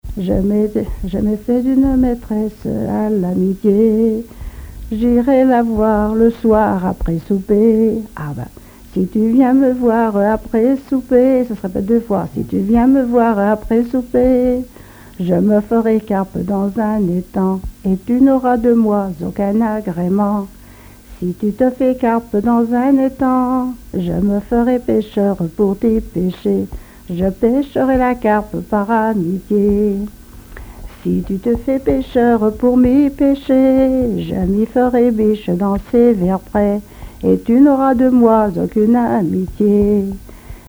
Genre énumérative
collecte en Vendée
Pièce musicale inédite